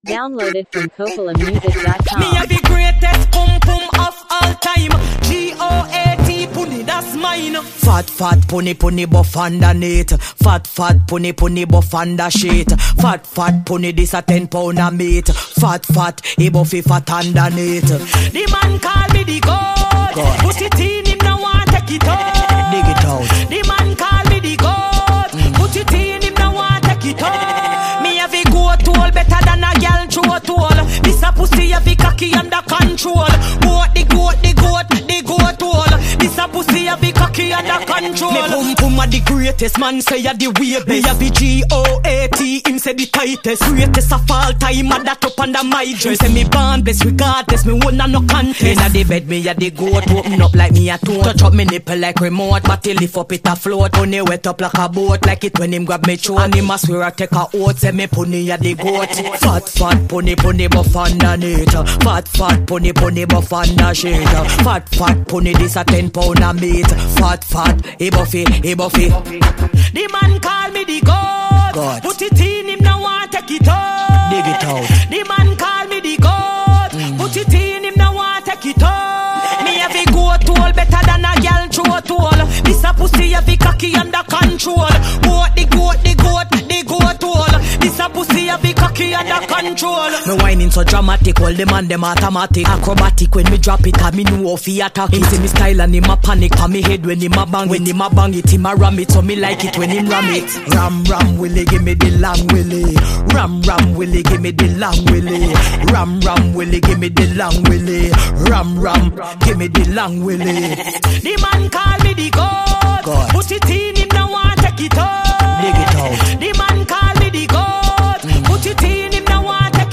dancehall
With strong lyrics and an aggressive, empowering vibe